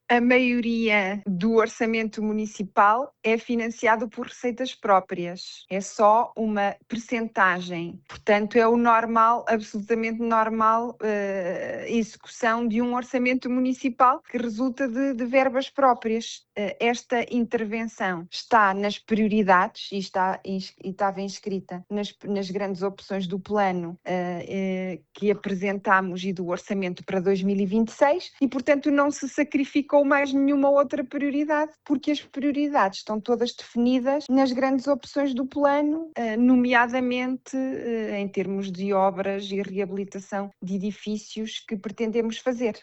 A autarca garante que o encerramento é temporário e que já estão asseguradas alternativas para os utilizadores da infraestrutura durante o período das obras: